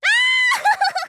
Lulu's voice from the official Japanese site for WarioWare: Move It!
WWMI_JP_Site_Lulu_Voice.wav